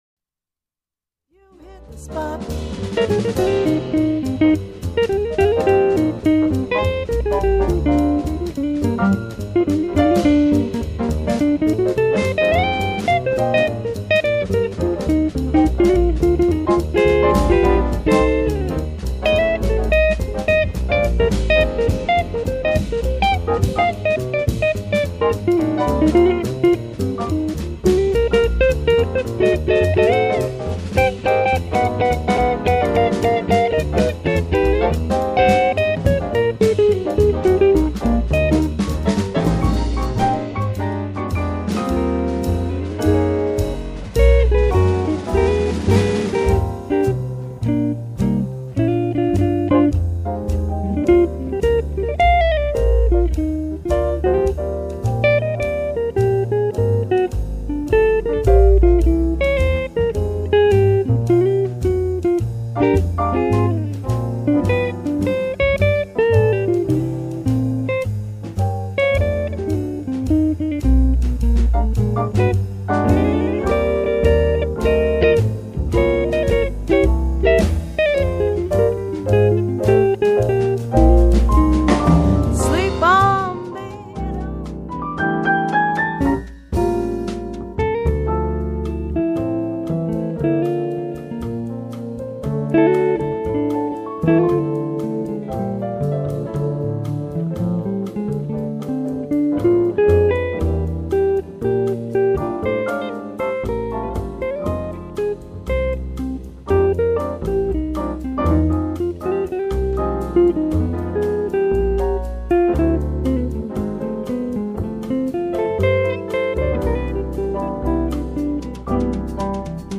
Jazz & Blues Demo